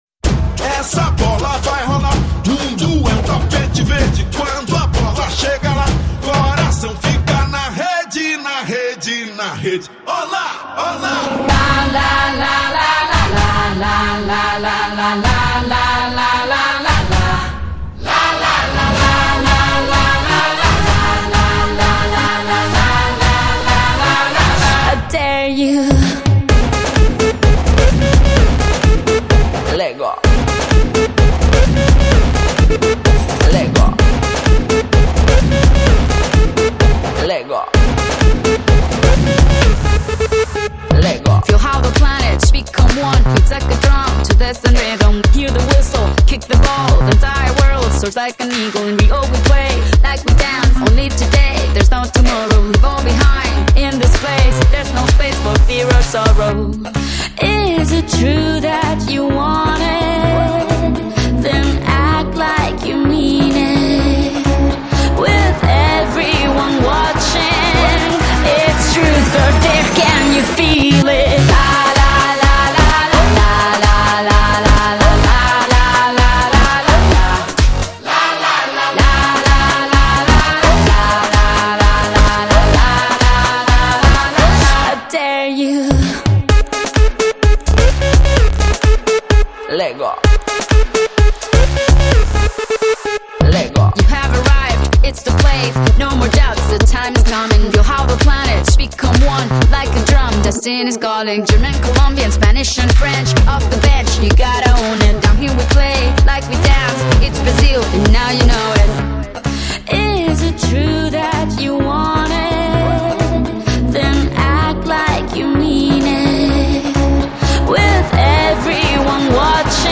آهنگ خارجی معروف خارجی برای ورزش زومبا
آهنگ خارجی شاد